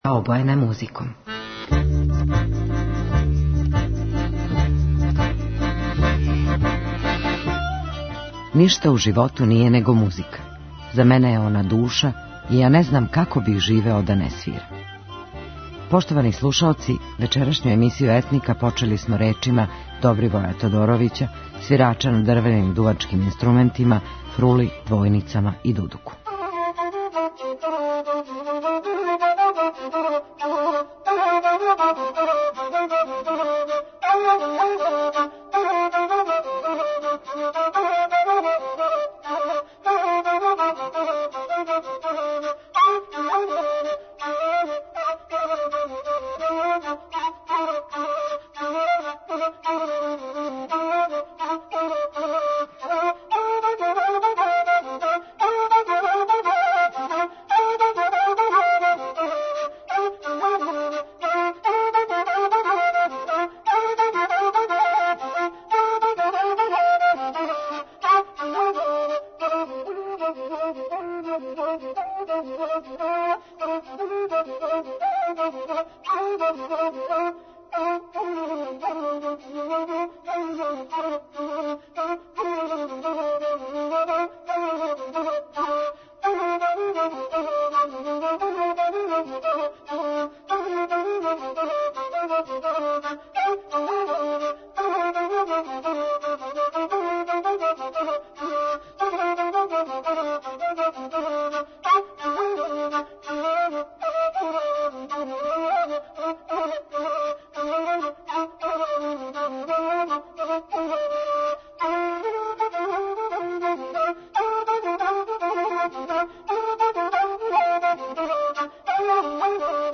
самоуком свирачу на дрвеним дувачким инструментима: фрули, дудуку и двојницама.